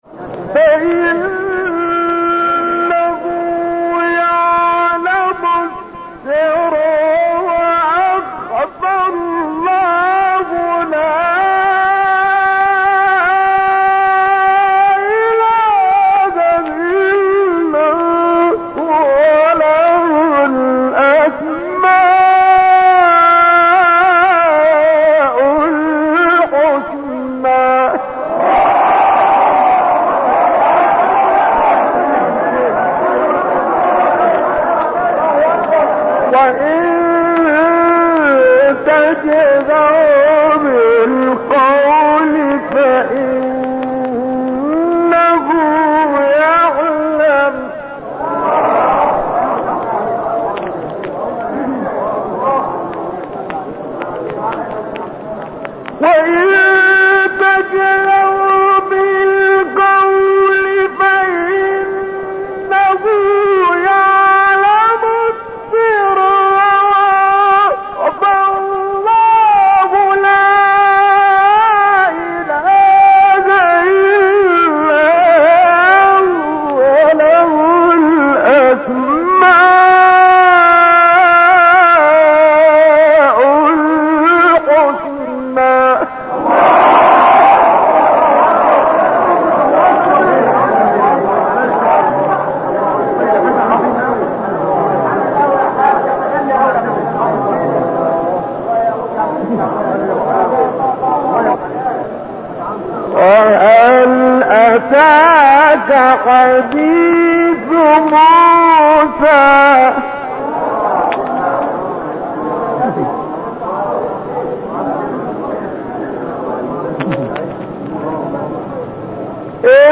آیه 7-14 سوره طه استاد شعبان صیاد | نغمات قرآن | دانلود تلاوت قرآن